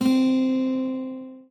guitar_c1.ogg